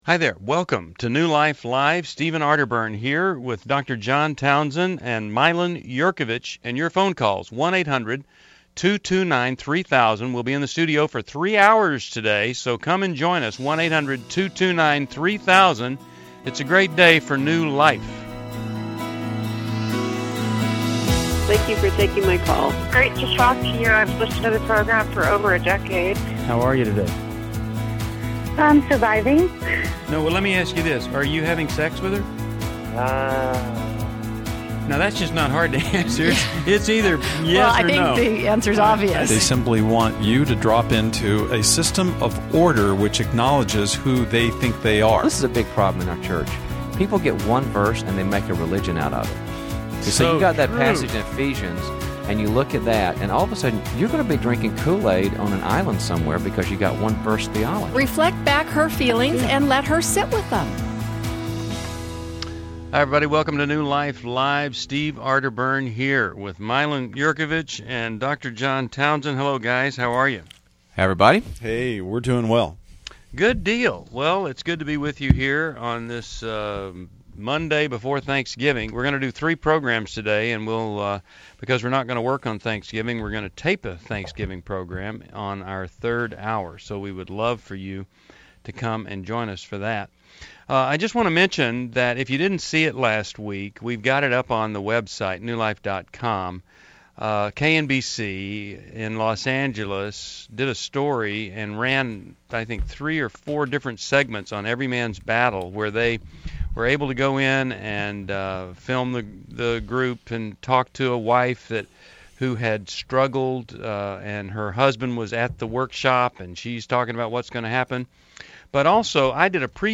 Caller Questions: How do I set boundaries with my controlling husband?